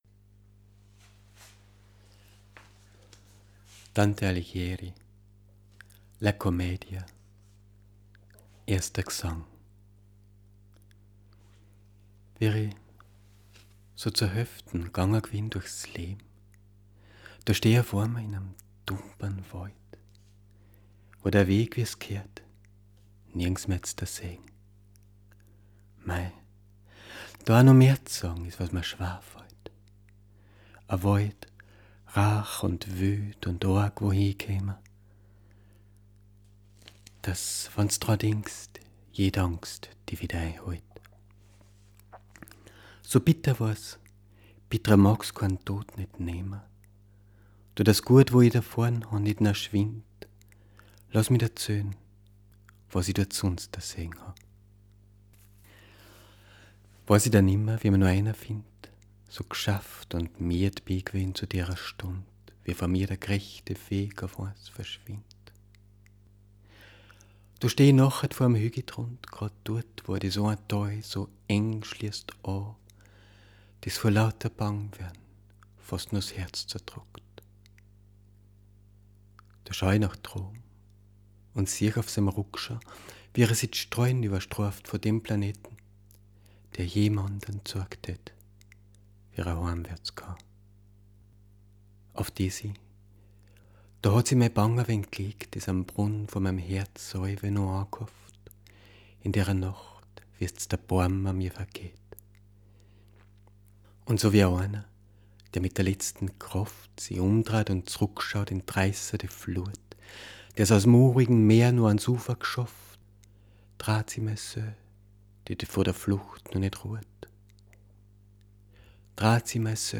- ♫ Audio-Skizze - (one-take recording - verleser ausgschnittn) ♫